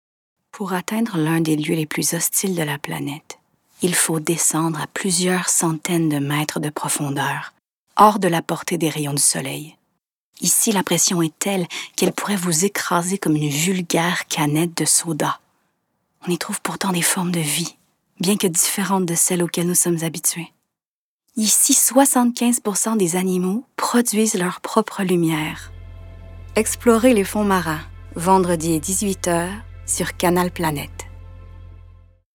Âge naturelle de la voix Jeune adulte
Timbre Médium - Grave - Petit grain chaleureux
Les fonds Marins - Narration Documentaire - Inspirante - Québécois naturel / Annonceuse télé